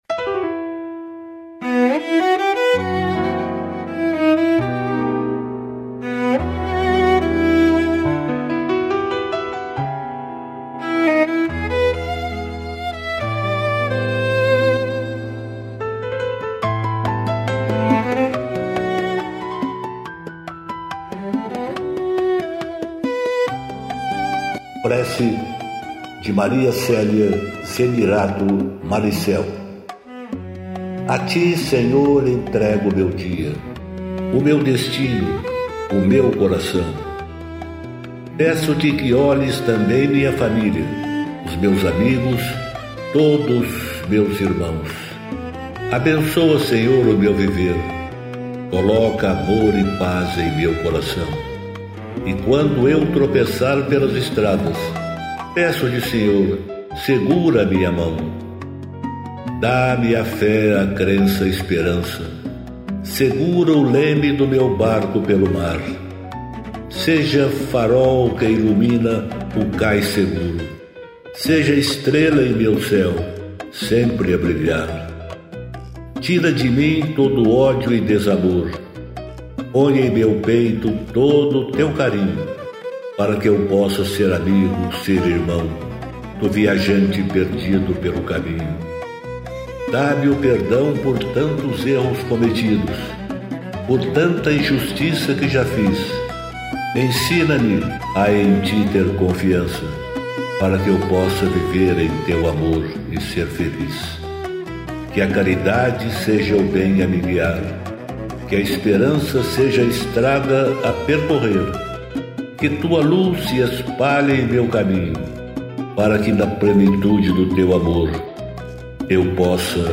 música e arranjo: IA